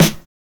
• 2000s Acoustic Snare Sound E Key 84.wav
Royality free snare drum tuned to the E note. Loudest frequency: 2201Hz